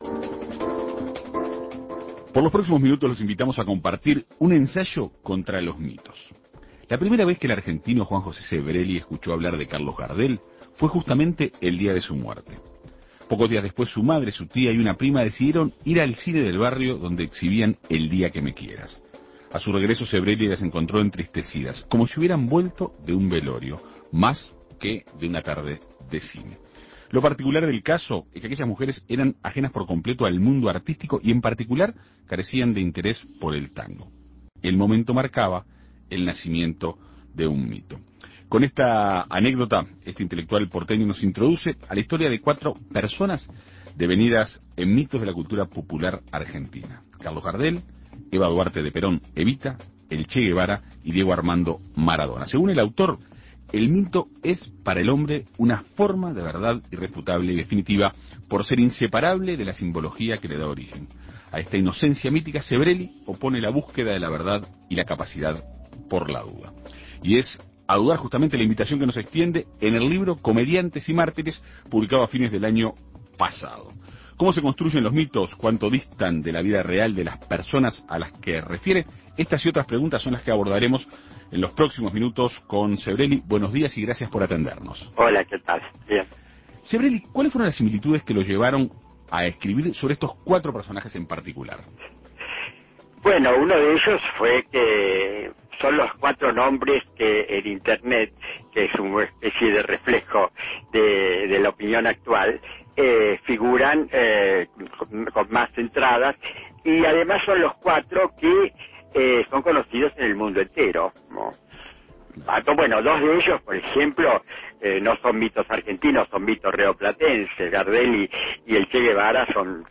Para conocer más sobre esta propuesta, En Perspectiva Segunda Mañana dialogó el escritor argentino.